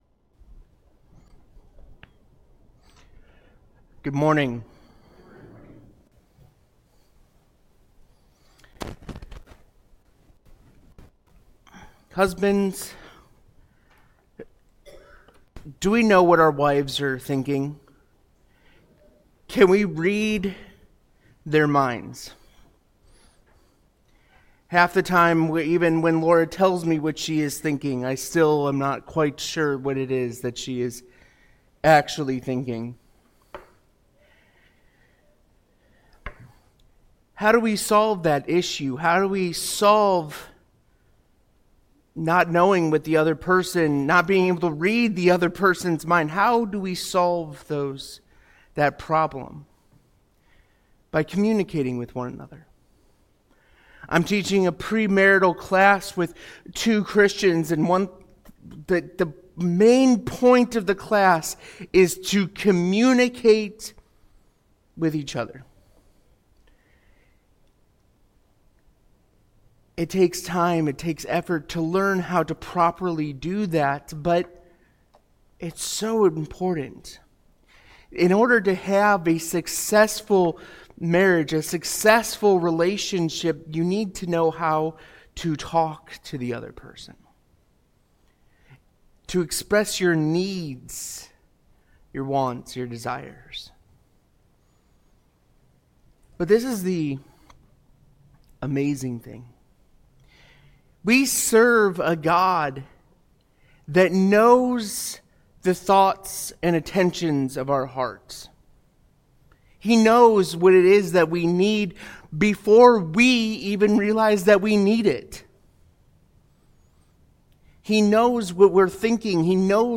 Sunday Sermons When in Doubt...